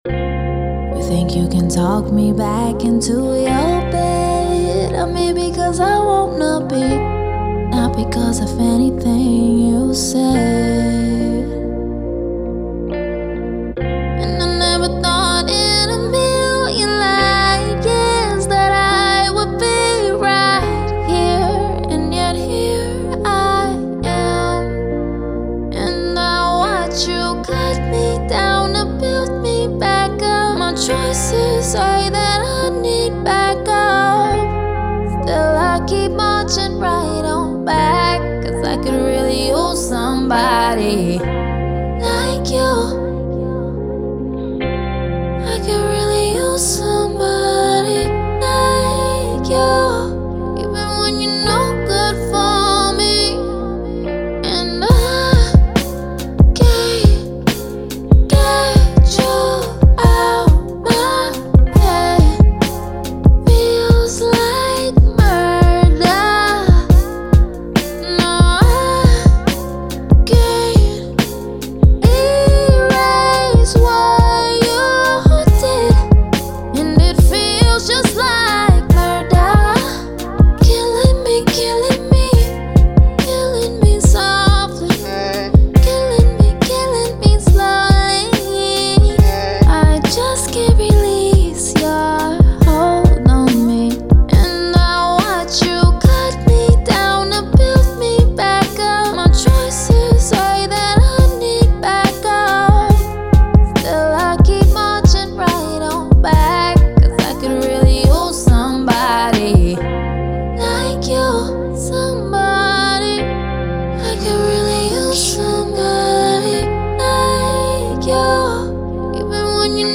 R&B, Pop
F Minor